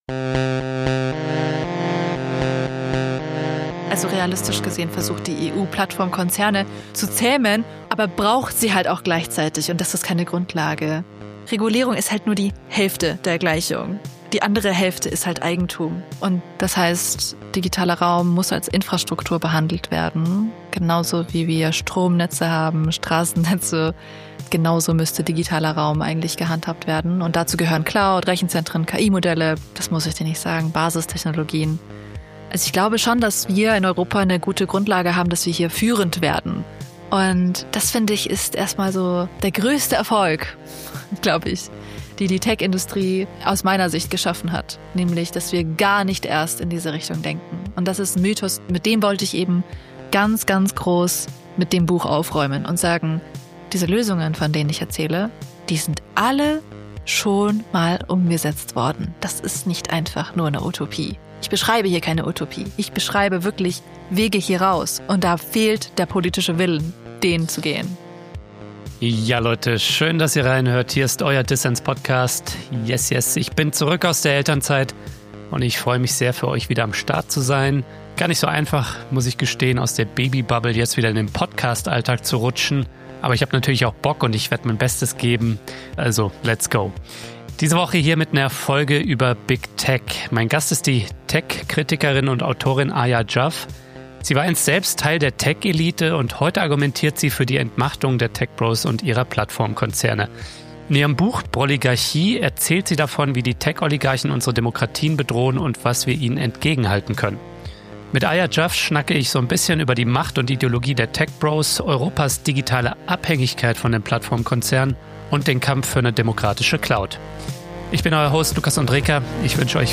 Ein Gespräch über die Macht und Ideologie der Tech-Bros, Europas digitale Abhängigkeit von den Plattformkonzernen und den Kampf für eine demokratische Cloud.